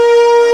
STRINGO.WAV